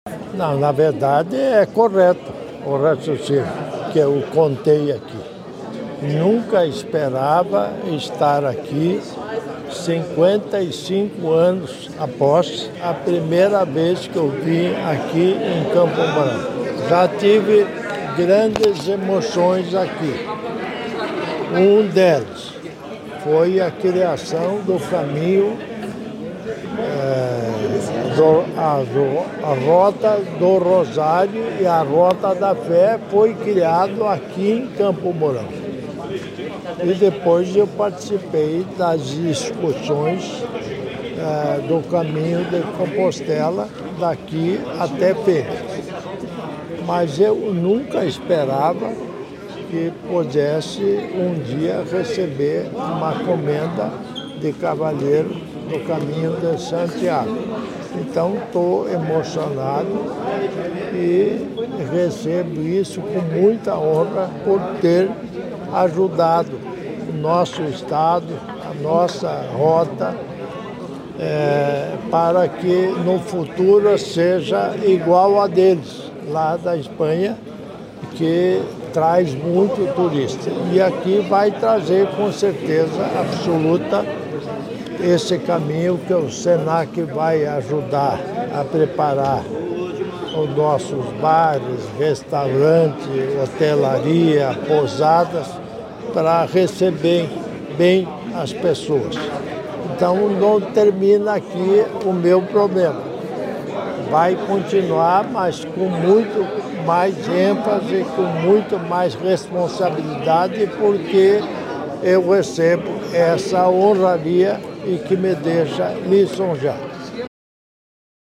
Sonora do governador em exercício Darci Piana, sobre a posse como cavaleiro e consolidação do Caminho Iniciático de Santiago em Campo Mourão | Governo do Estado do Paraná
Sonora do governador em exercício Darci Piana, sobre a posse como cavaleiro e consolidação do Caminho Iniciático de Santiago em Campo Mourão